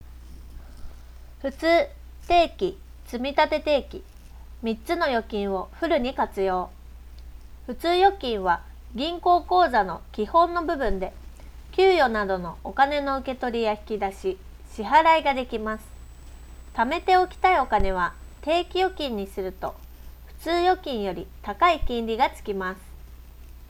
マイク感度は高く、装着者の声を明瞭に拾い上げることができていた。
▼ST2 PRO Nebulaの内蔵マイクで拾った音声単体
録音音声を聴いても分かる通り、発言者の声をしっかりと明瞭に拾い上げており、相手側への伝わり方もクリア。
ノイズフィルター処理やマイクのシールド性能には改善の余地があると言えるが、そこまで気になるレベルの雑音でもない。
hidizs-st2-pro-nebula-earphone-review.wav